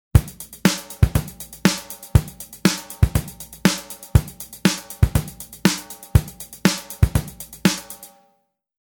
16ビート その1（BPM120）
シンプルな16ビートのパターンです。
3拍目のひとつ前に置かれたキックによって、リズムにアクセントが加わっています。
ちなみにこのキックがなかったとしても、ハイハットを16分で刻んでいるので、こちらは16ビートということになります。
drum-16beat-1.mp3